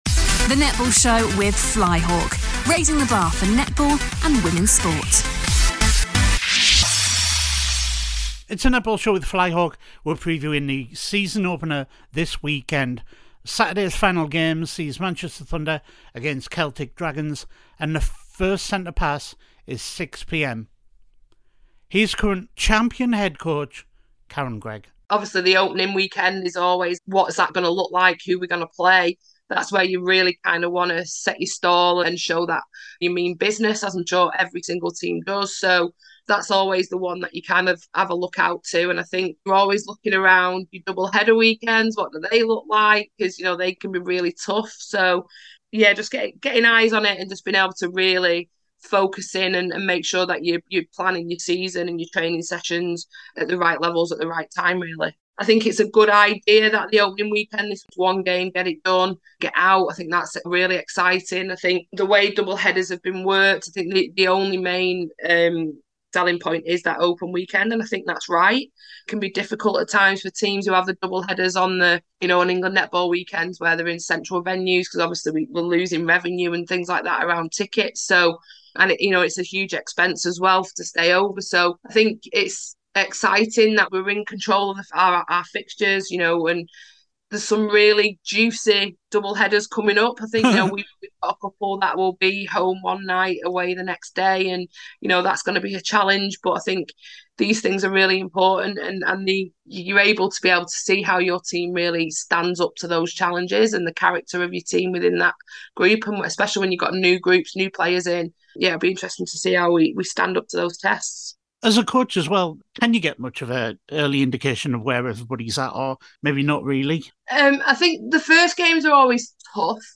Hear from players and coaches from Thunder and Dragons as they start their 2023 season tomorrow